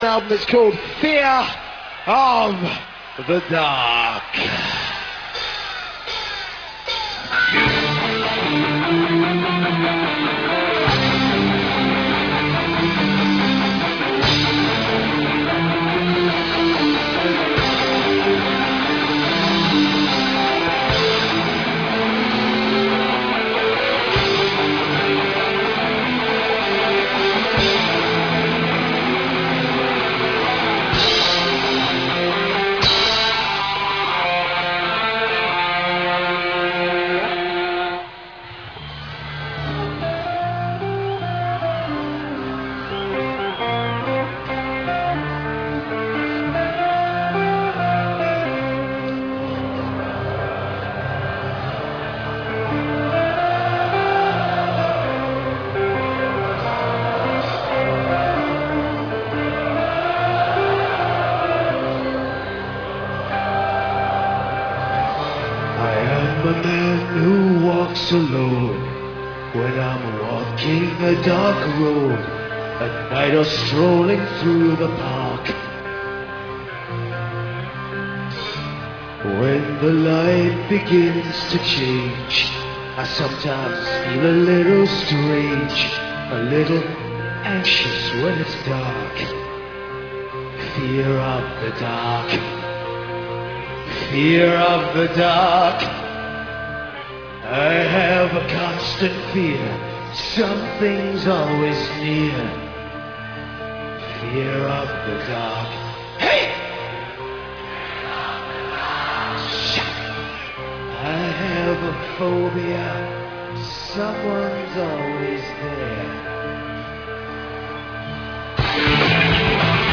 MayDay Festival 1993, Italy